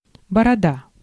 Ääntäminen
IPA: [baʁb]